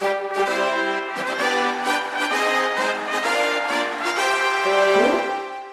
На этой странице собраны яркие и радостные звуки детского праздника: смех, веселые мелодии, фанфары и другие аудиоэффекты.